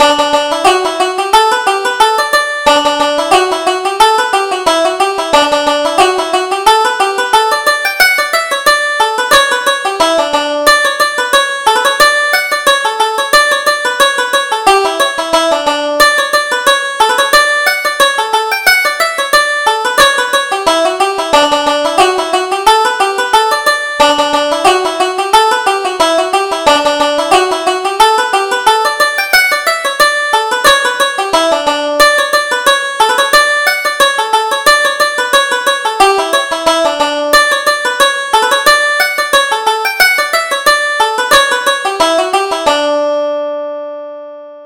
Reel: The Mills Are Grinding - 2nd Setting